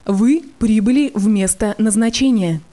Звуки навигатора
Звук прибытия в место назначения